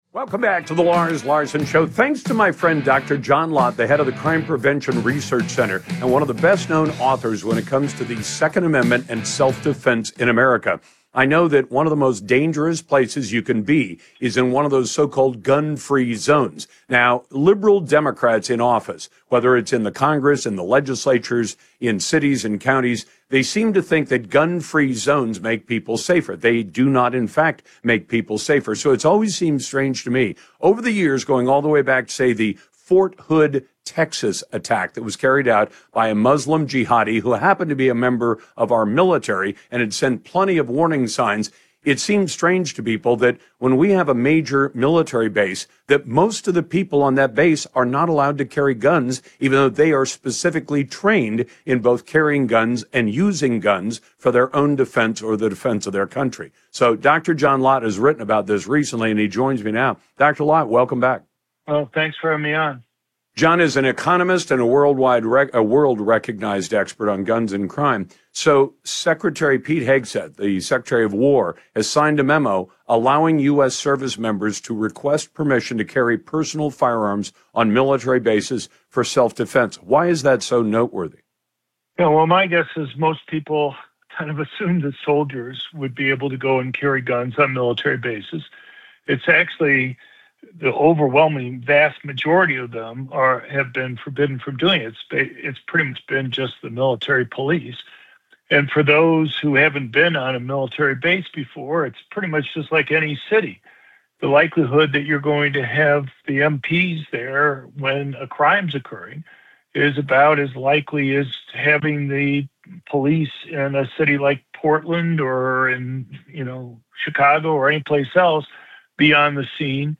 Dr. John Lott talked to Lars Larson on his national radio show about his latest op-ed at Real Clear Politics on the end of gun-free zones at military bases.